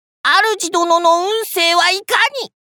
鳴狐抽籤語音 中吉